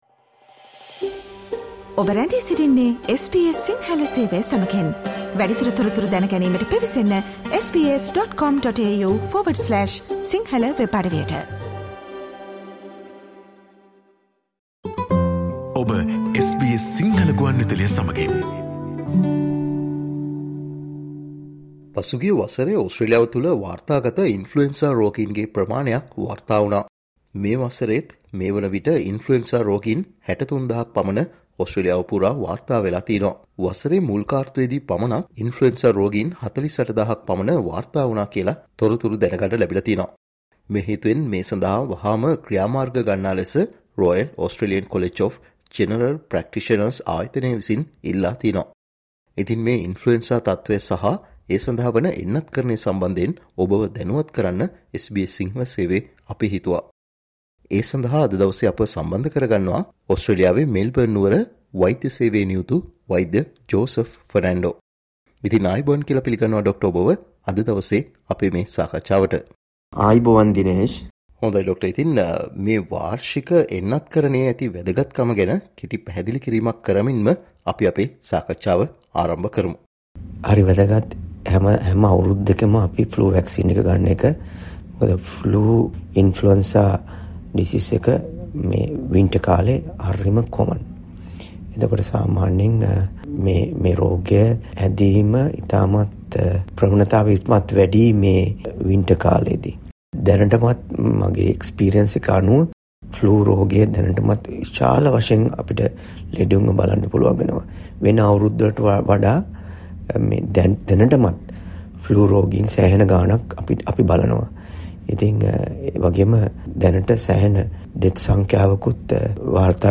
SBS සිංහල සේවය සිදුකල සාකච්ඡාව.